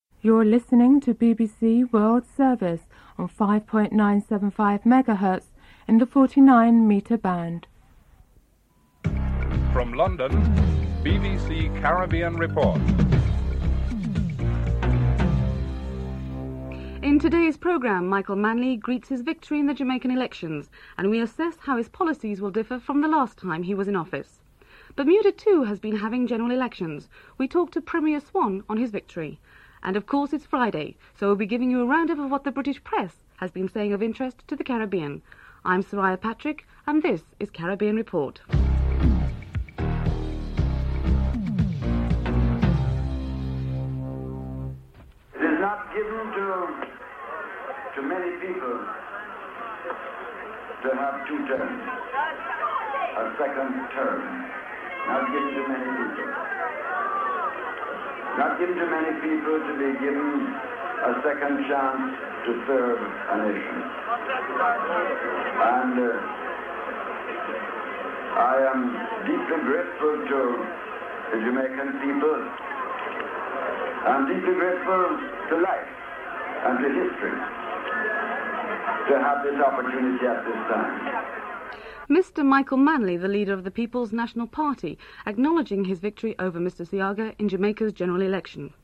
2. Michael Manley speaks of his success at the polls and his domestic and foreign policies inclusive of relations with Washington and Cuba (00:00-04:34)
3. Interviews of persons in Britain with Jamaican connections on the recent Jamaican elections (04:35-06:15)